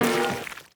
Universal UI SFX / Clicks
UIClick_Menu Select Organic Decay.wav